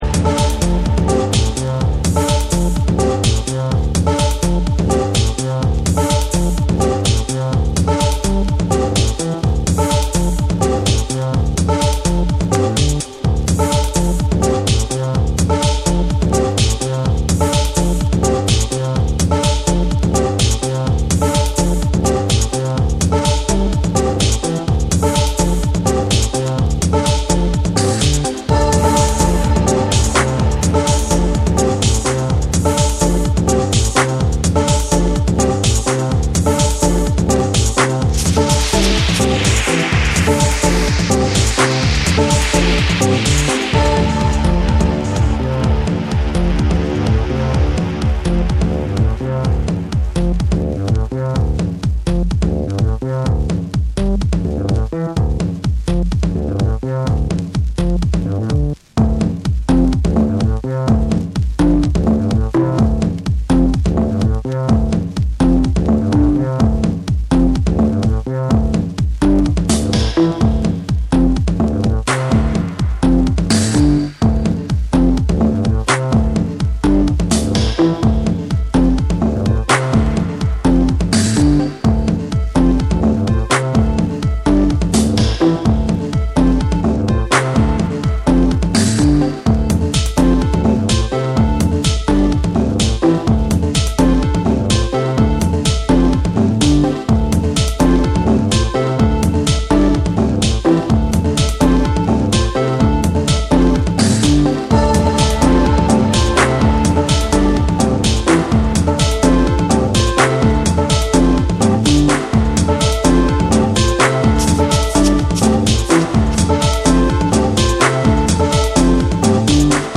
柔らかく叩き打つトライバルなリズムに神秘的なシンセのメロディーが溶け込む
JAPANESE / TECHNO & HOUSE / ORGANIC GROOVE